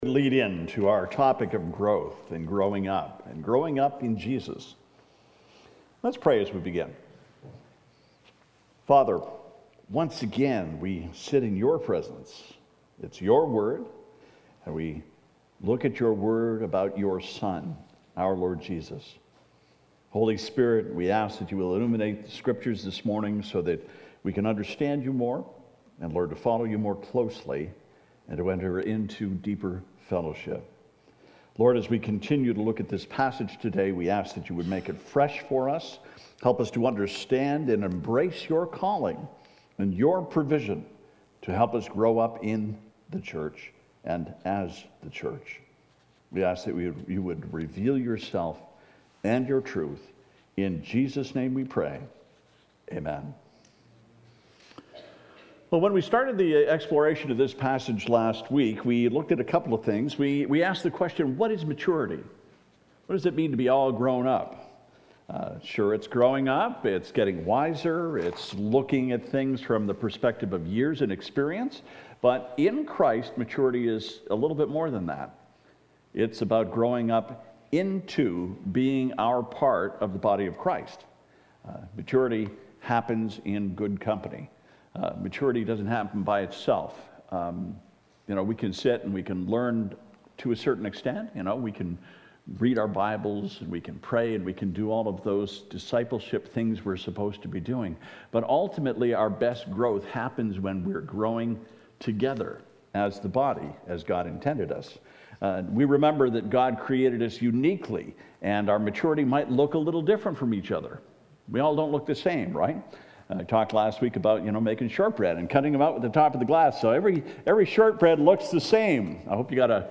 “Grow Up” Part 2: Ephesians 4:1-16 « FABIC Sermons